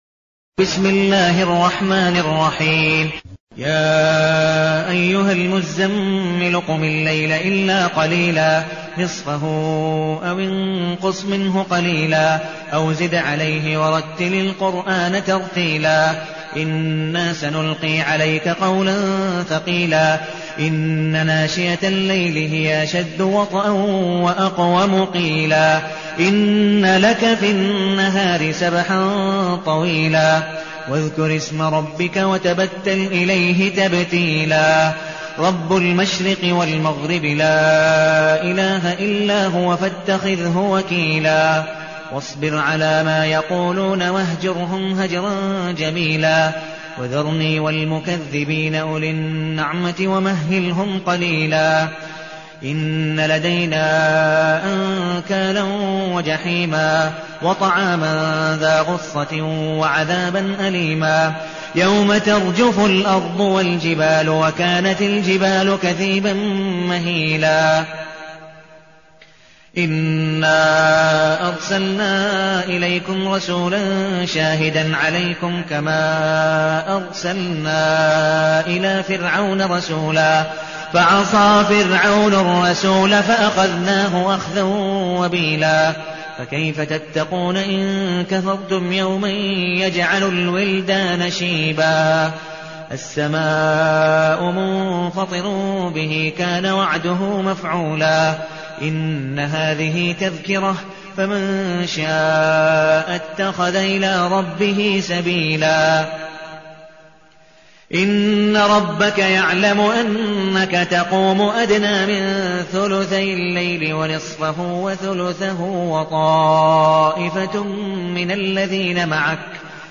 المكان: المسجد النبوي الشيخ: عبدالودود بن مقبول حنيف عبدالودود بن مقبول حنيف المزمل